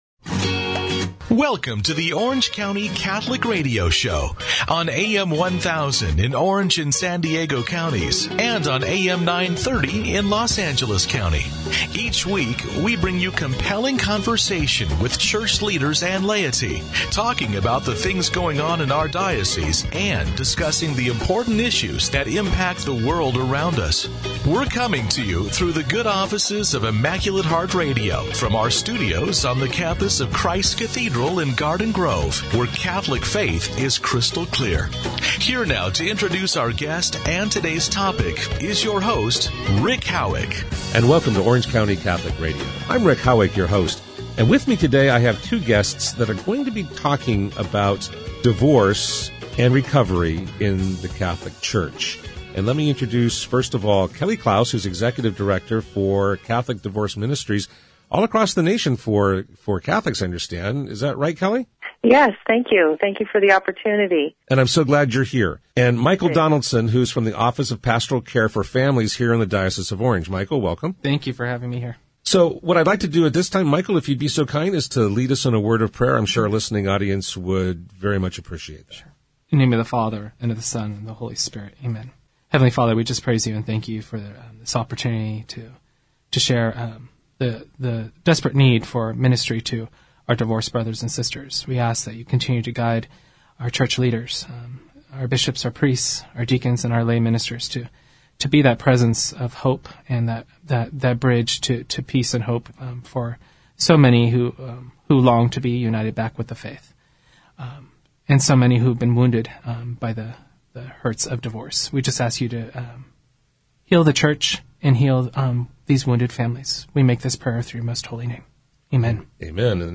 EPISODE#116OC CATHOLIC RADIO